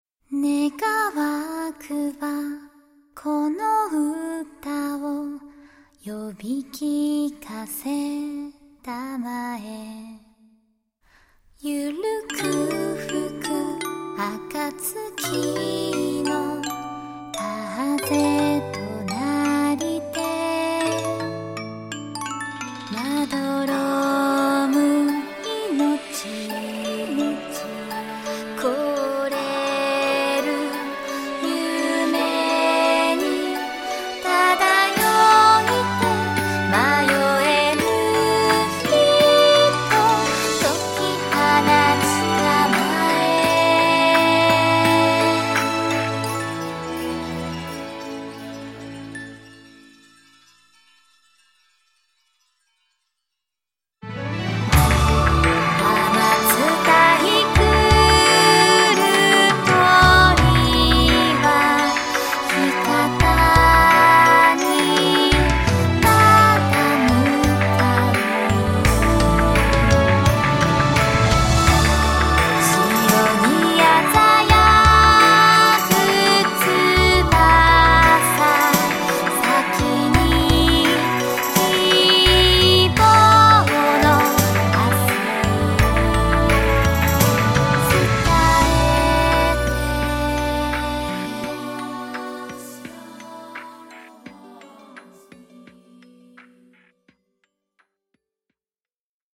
透き通ったメインメロディが一本通っていて、 研ぎ澄まされた感覚のある曲